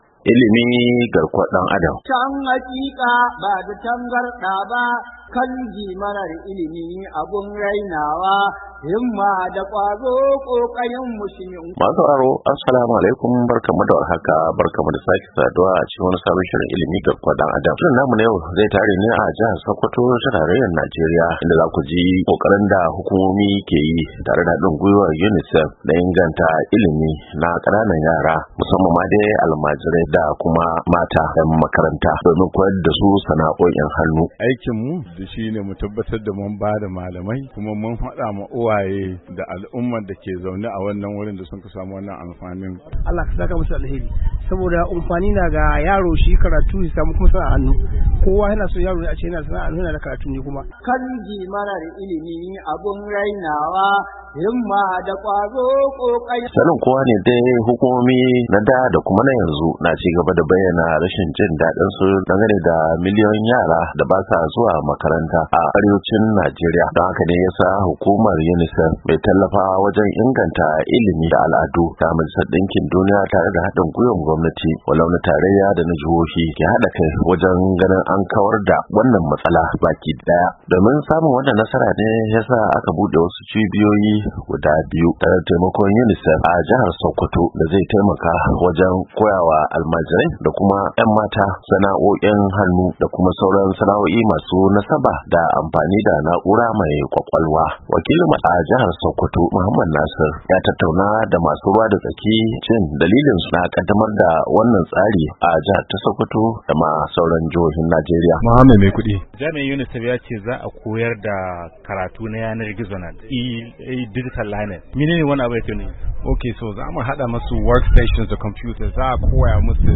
A shirin Ilmi na wannan makon mun tattauna ne da wani wakilin hukumar UNICEF akan batun samar da cibiyoyi guda biyu a Sokoto domin sanya Almajiri da yara mata masu tasowa koyon Dijital da sauran fasahohin sana'o'i a wani mataki na rage adadin miliyoyin yaran da ba sa zuwa makaranta.